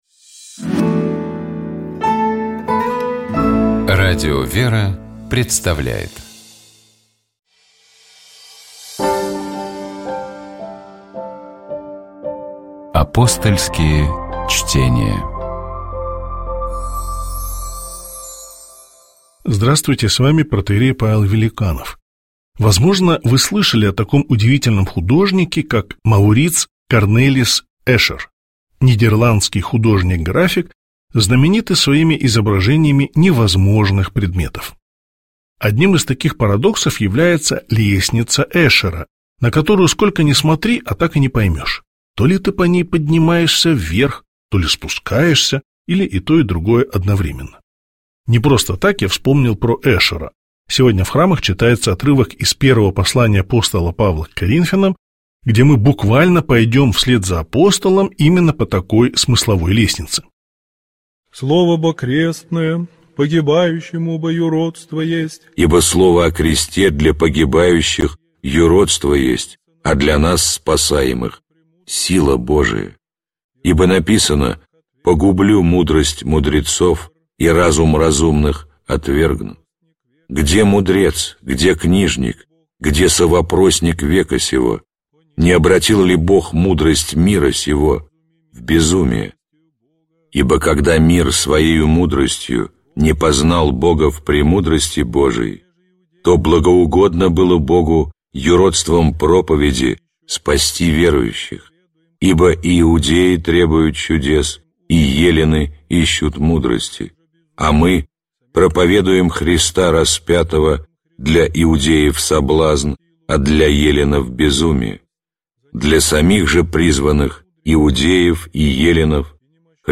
Комментирует протоиерей